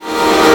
VEC3 FX Athmosphere 09.wav